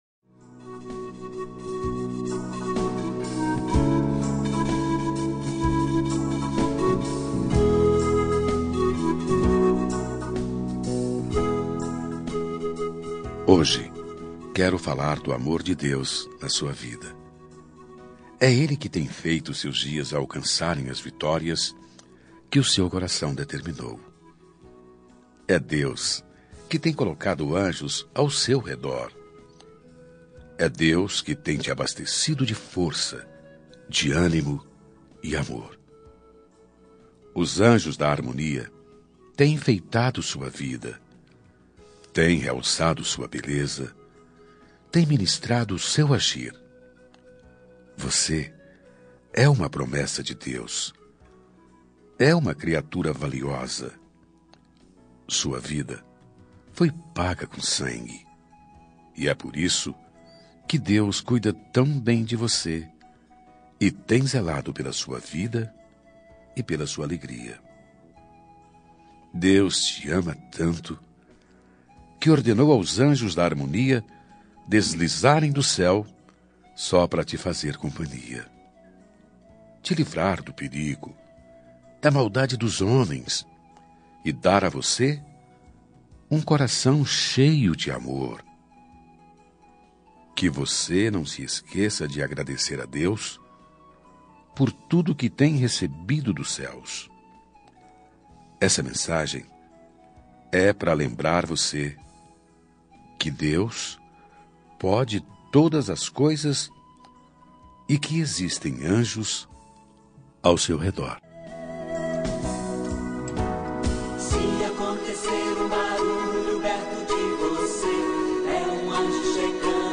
Aniversário Religioso – Voz Masculina – Cód: 7400 – Anjos
74-religiosa-masc.m4a